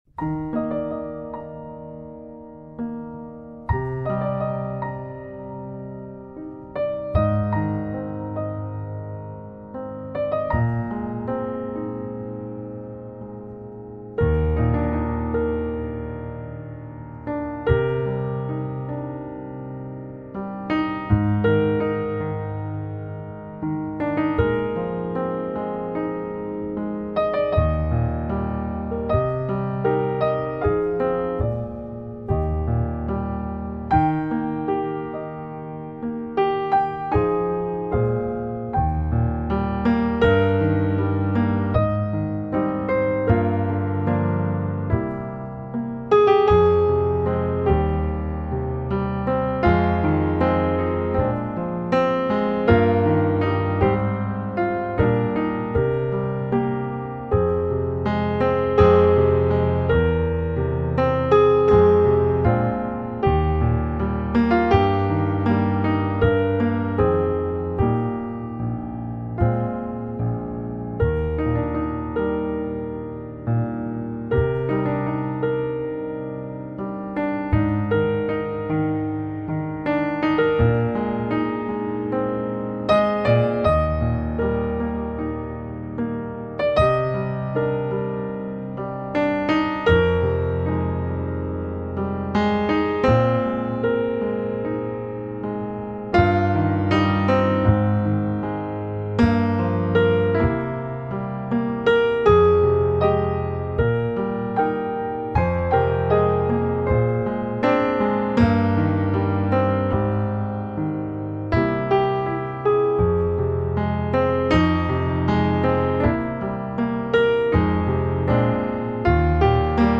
Some of you may classify the sound as New Age.